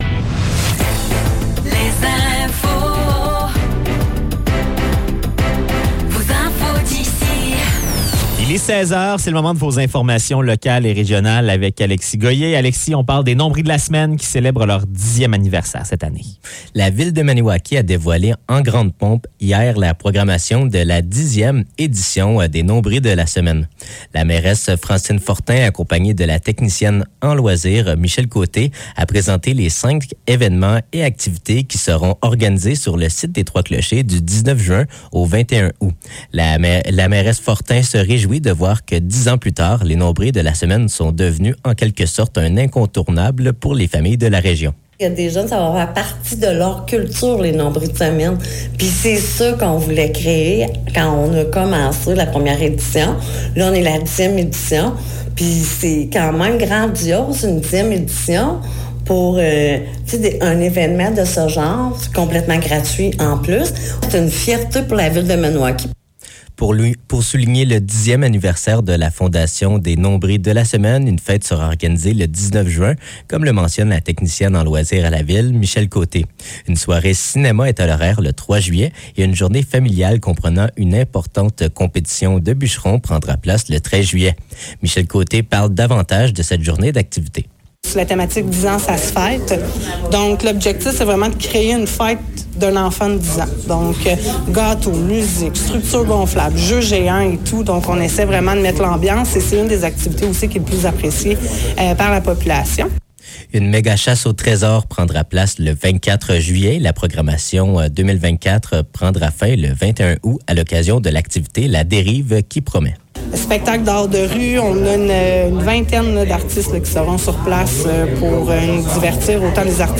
Nouvelles locales - 23 mai 2024 - 16 h